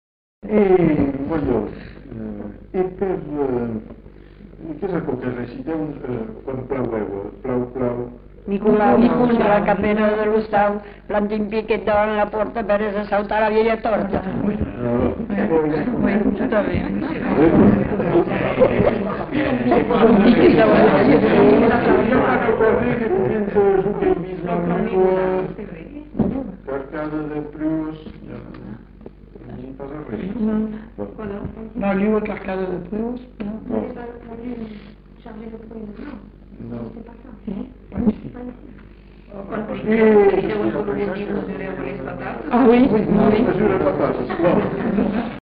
Aire culturelle : Bazadais
Lieu : Villandraut
Genre : forme brève
Effectif : 1
Type de voix : voix de femme
Production du son : récité
Classification : formulette enfantine